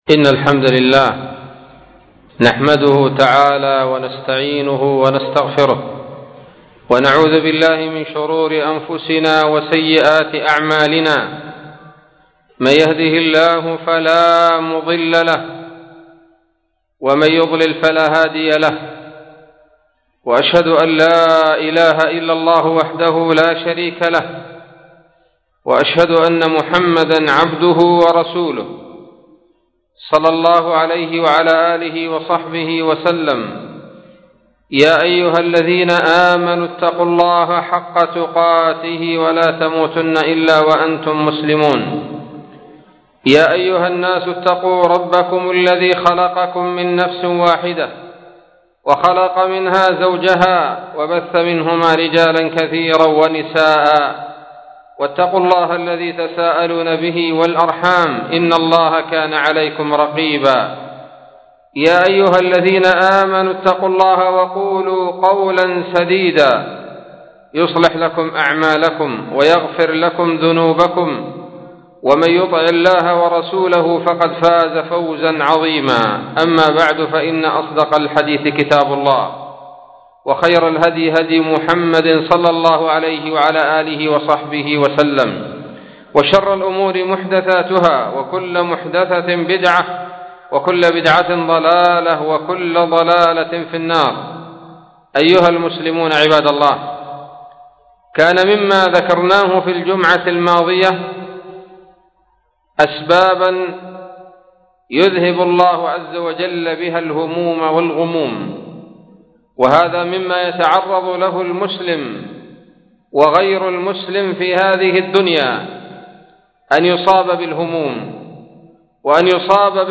خطبة بعنوان : ((علاج الهموم [2]))